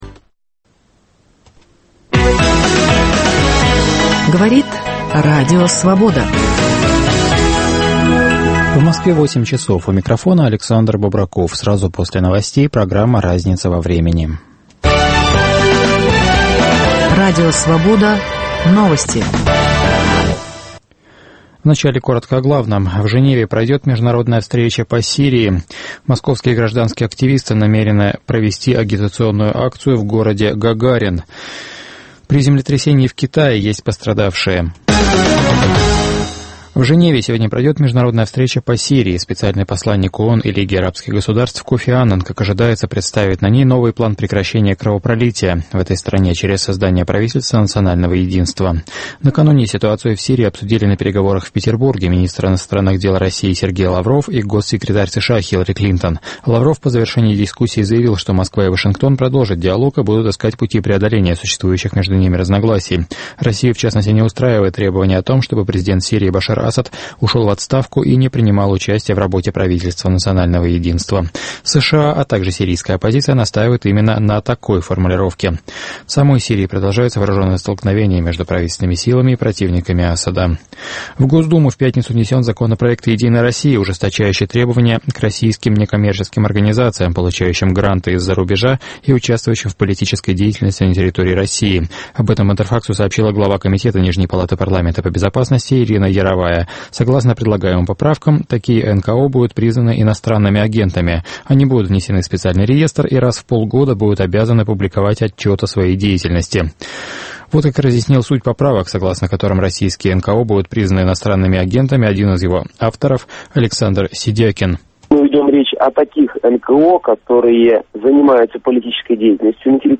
"Всесильный" Берия - "слабое звено" в системе послесталинской власти В передачу включены фрагменты интервью (1996) с сыном и одной из любовниц Берия.